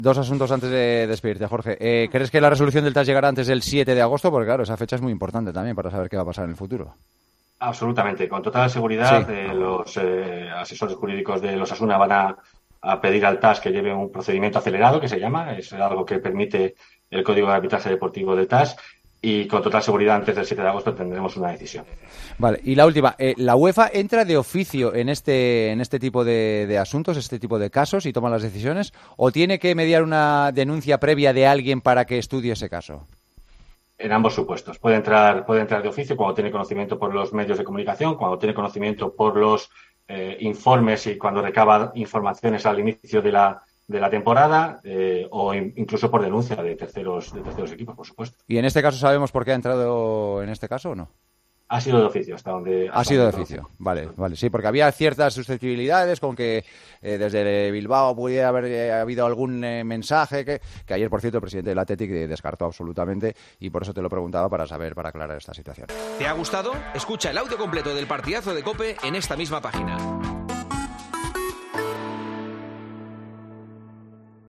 Un abogado, en El Partidazo de COPE, explica si un equipo pudo denunciar a Osasuna ante la UEFA